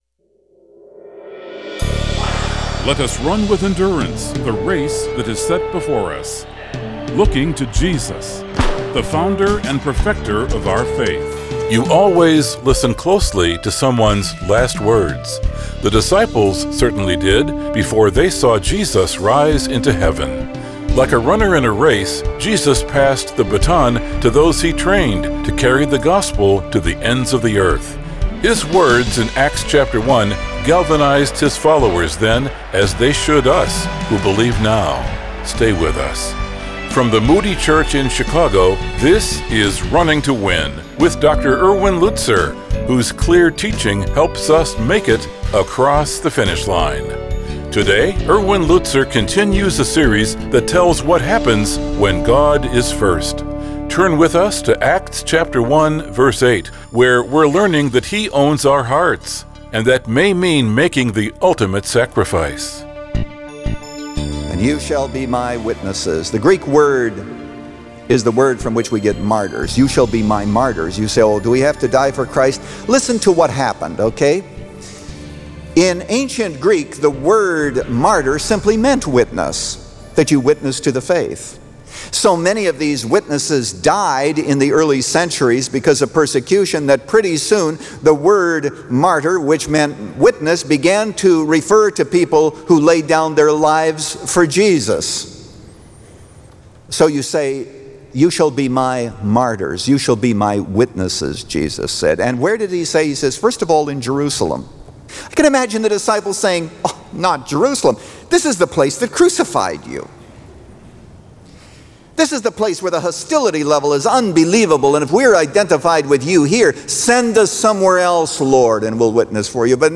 He Owns Our Hearts – Part 2 of 3 | Radio Programs | Running to Win - 15 Minutes | Moody Church Media